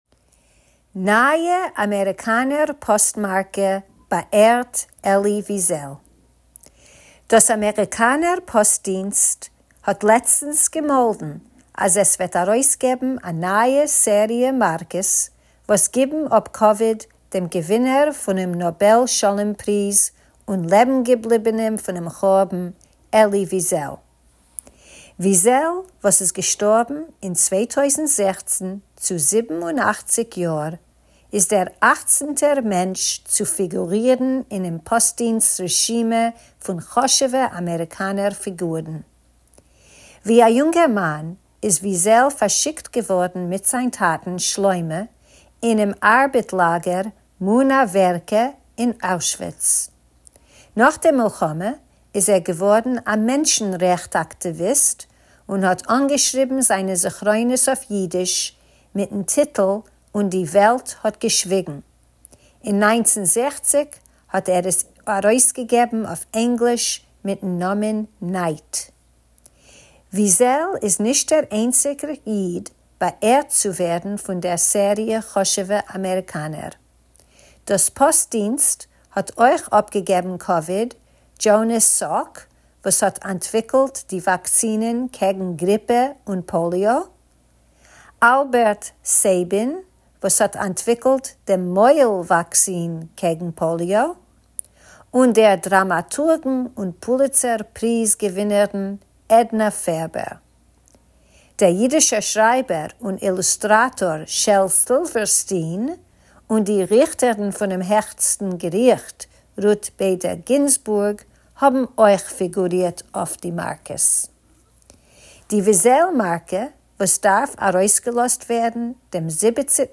Tidbits is a Forverts feature of easy news briefs in Yiddish that you can listen to or read, or both!